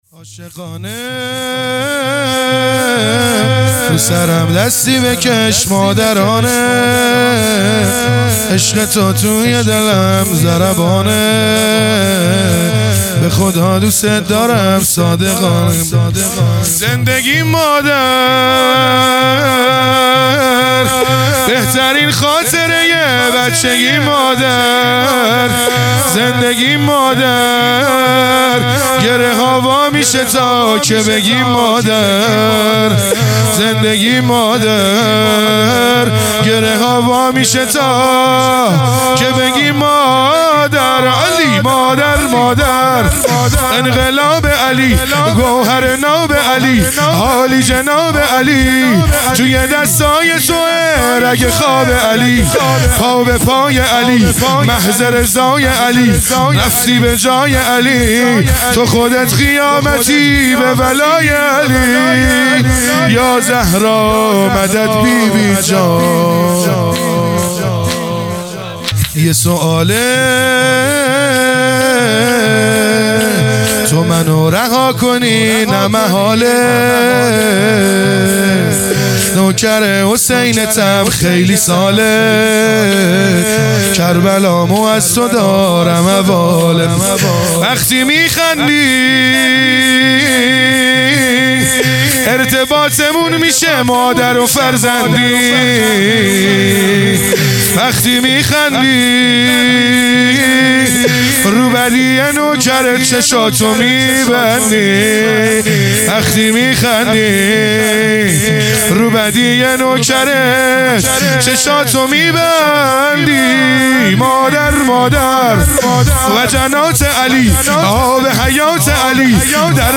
دهه دوم فاطمیه | شب اول | شور | عاشقانه رو سرم دستی بکش مادرانه